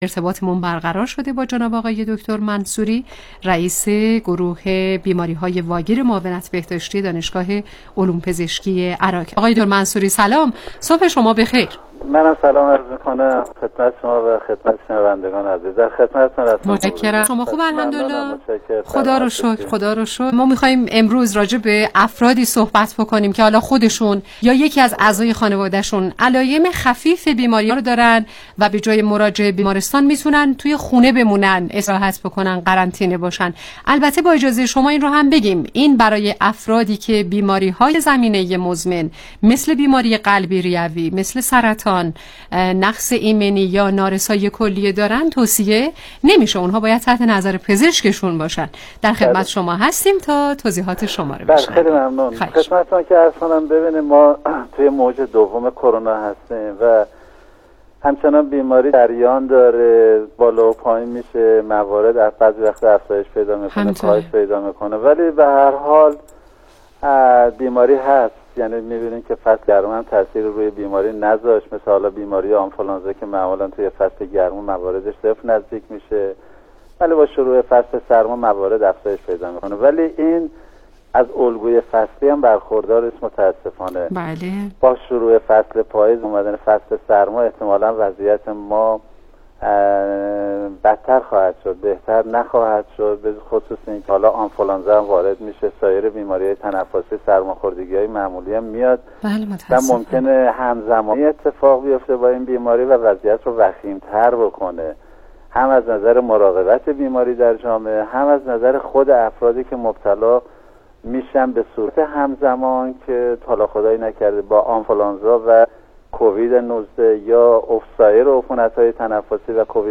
گفتگوی تلفنی
برنامه رادیویی